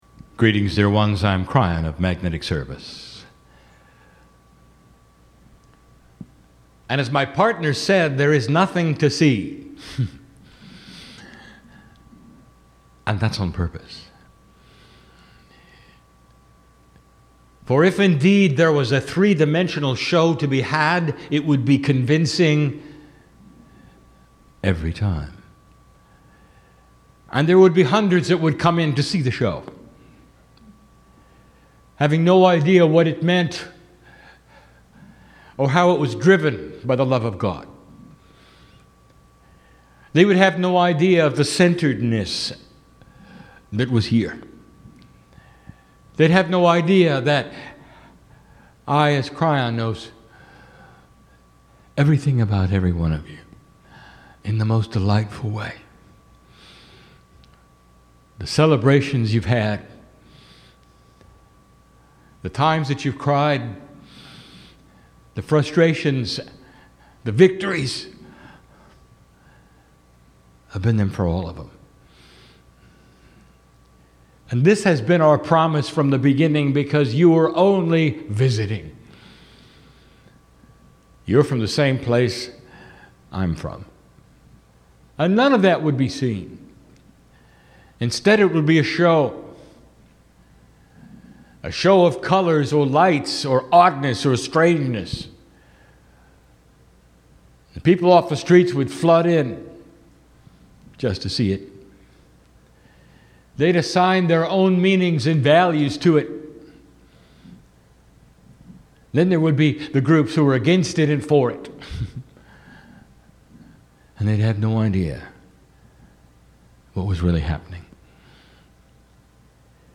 Mini Channelling The Recalibration of Belief